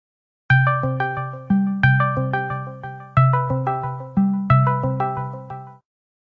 Discord Incoming Call Meme Sound sound effects free download